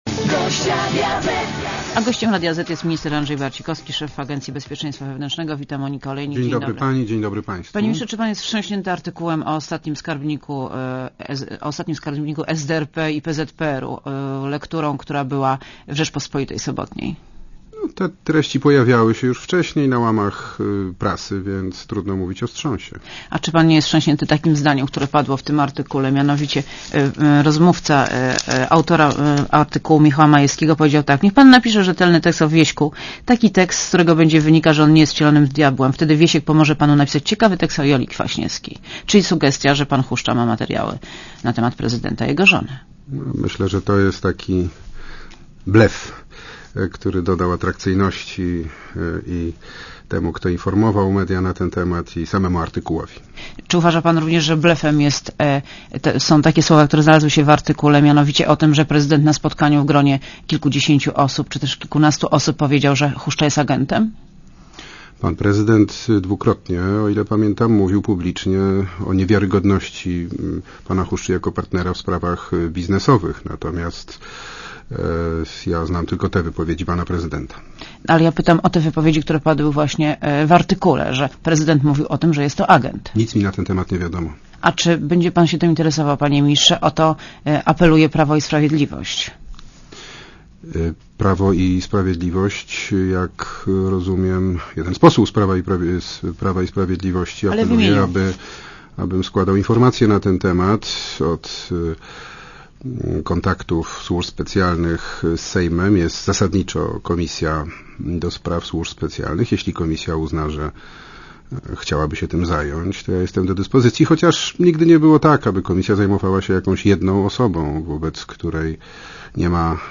A gościem Radia Zet jest minister Andrzej Barcikowski, szef Agencji Bezpieczeństwa Wewnętrznego. Wita Monika Olejnik.